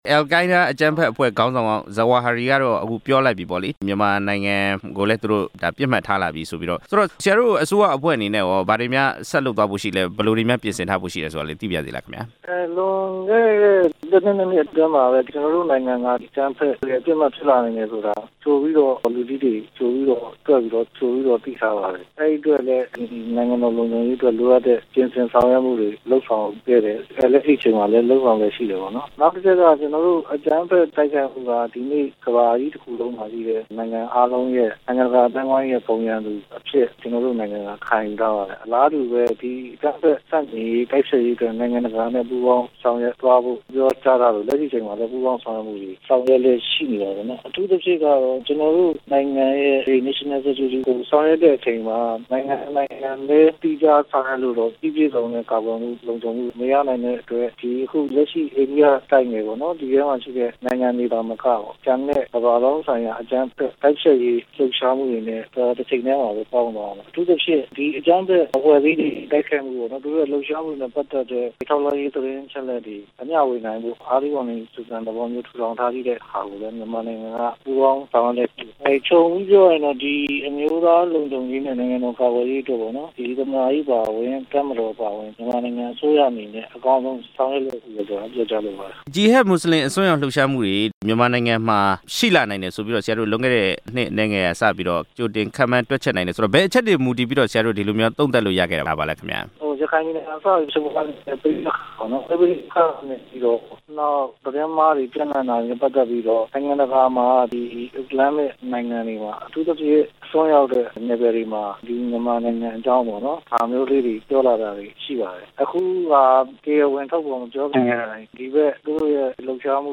အယ်လ်ကိုင်းဒါး အကြမ်းဖက်အဖွဲ့ ခြိမ်းခြောက်မှုကိစ္စ ဆက်သွယ်မေးမြန်းချက်